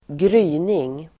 Uttal: [²gr'y:ning]